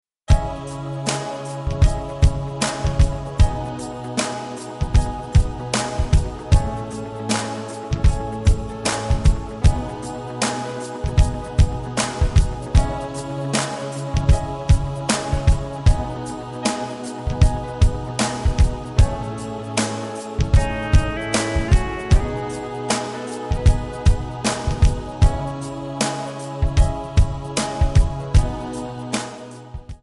MPEG 1 Layer 3 (Stereo)
Backing track Karaoke
Country, 2000s